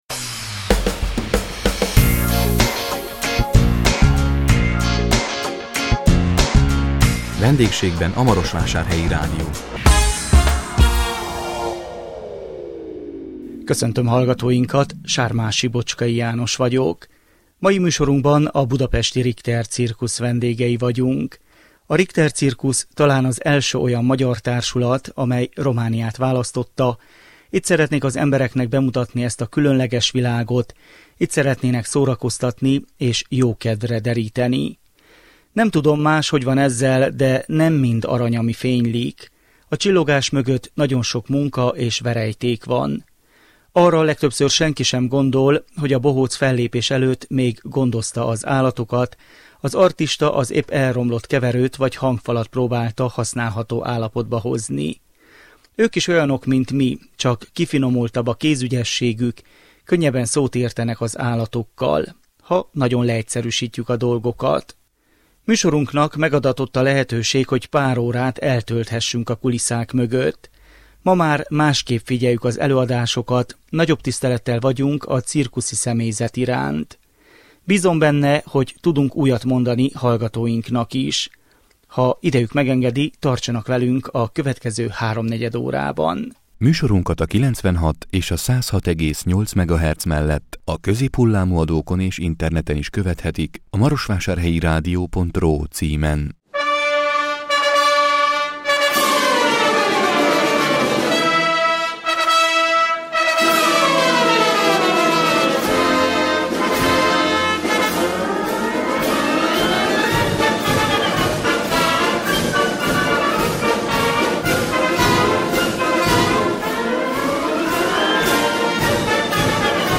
A 2018 május 31-én jelentkező Vendégségben a Marosvásárhelyi Rádió című műsorunkban a budapesti Richter Cirkusz vendégei voltunk.
Műsorunknak megadatott a lehetőség, hogy pár órát eltölthessünk a kulisszák mögött.